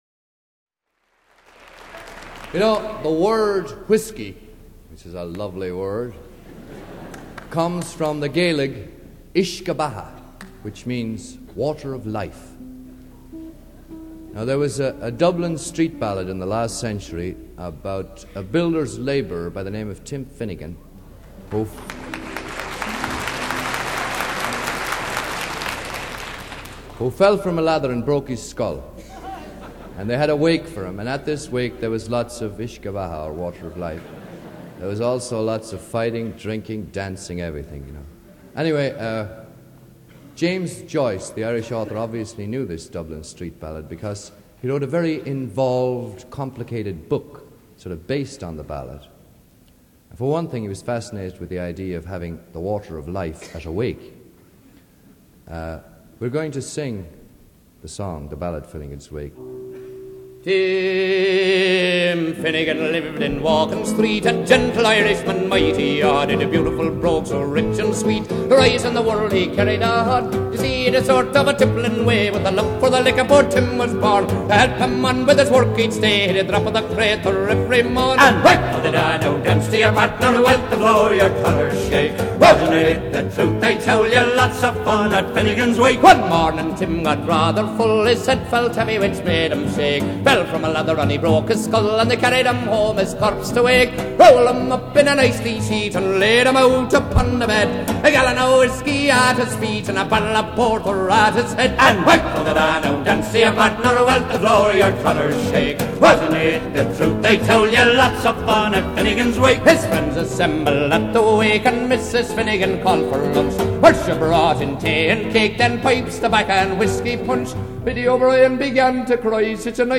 一首电子音乐 非常有气势 富有浓郁的神秘感 让人联想到古老的爱尔兰岛 仙气四溢